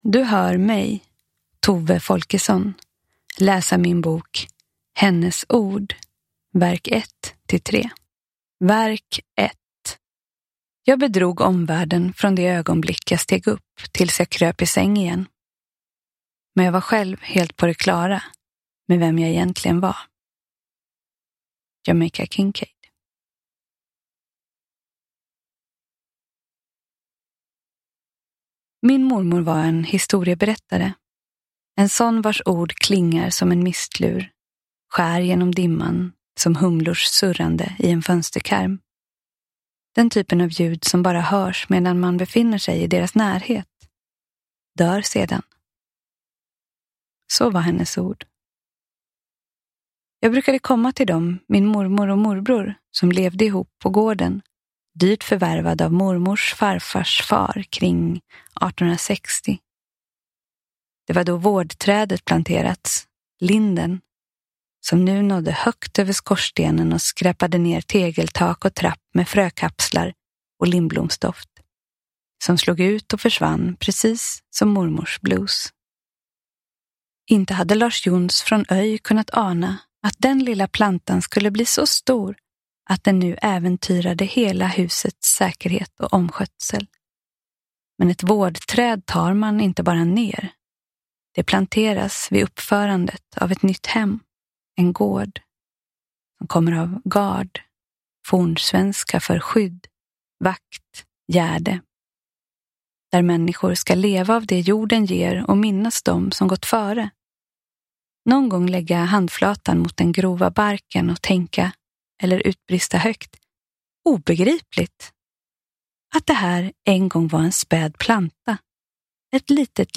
Uppläsare: Tove Folkesson
Ljudbok